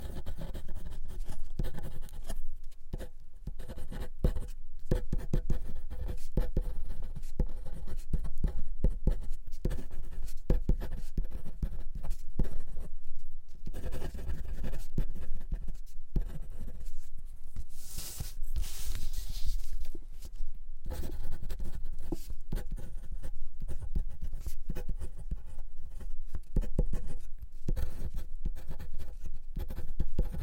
写作 " 在玻璃上的纸上快速书写的细尖笔 3
描述：记录在带有SM81和便宜的akg SDC的SD 702上，不记得哪一个只是想要变化。不打算作为立体声录音只有2个麦克风选项。没有EQ不低端滚动所以它有一个丰富的低端，你可以驯服品尝。